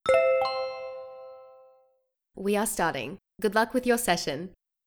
focus_alert_0.wav